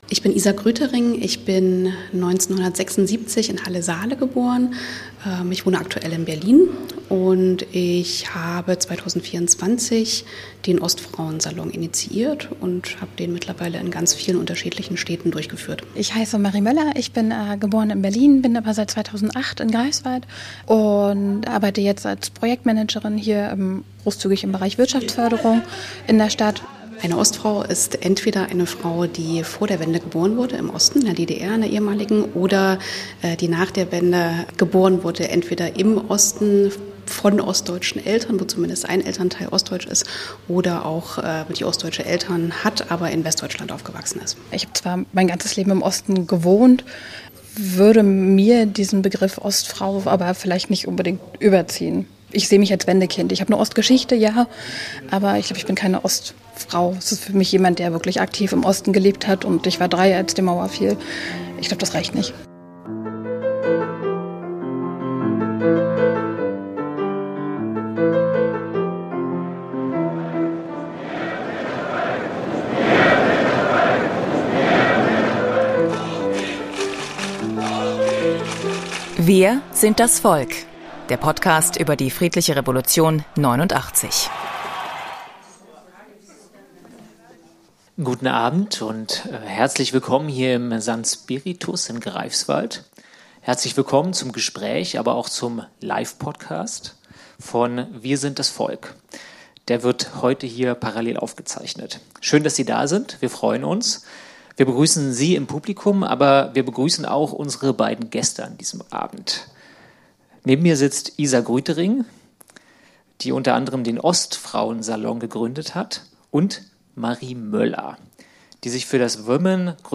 Der Live-Podcast wurde im Juni im St. Spiritus in Greifswald aufgezeichnet.